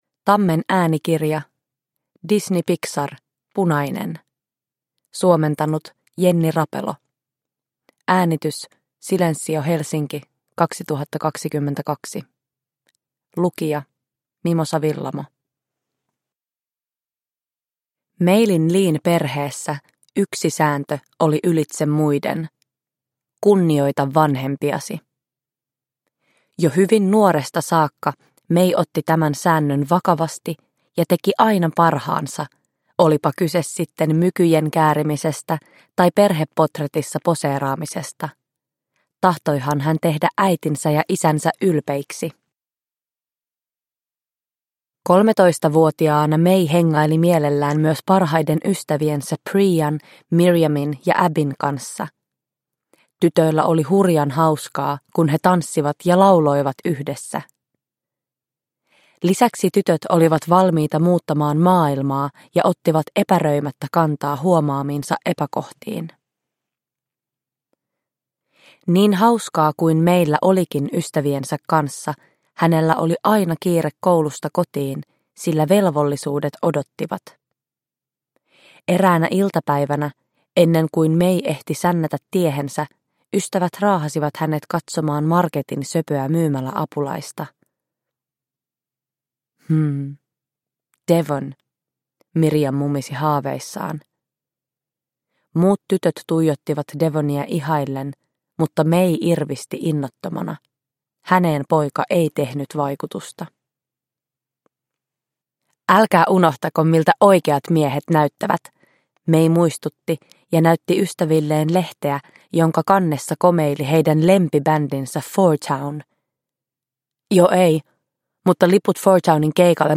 Disney Pixar. Punainen. Satuklassikot – Ljudbok – Laddas ner
Uppläsare: Mimosa Willamo